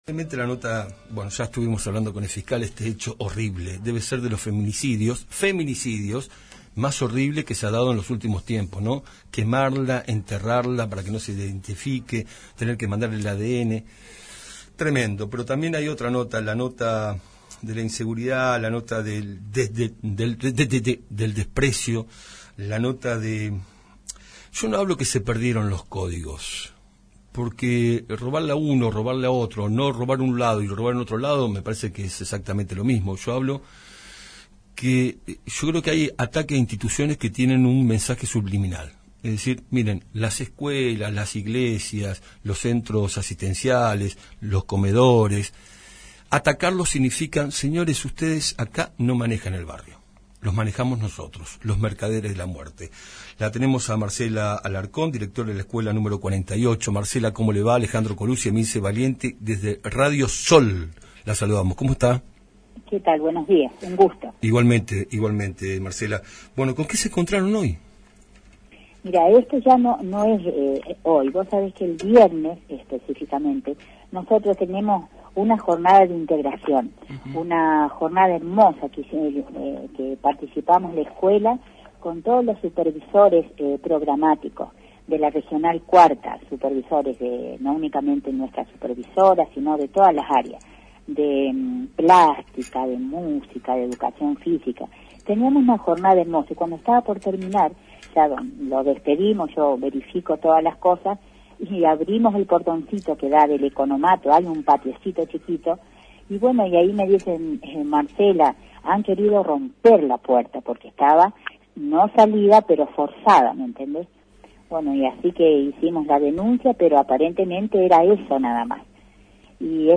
En diálogo con SOL 91.5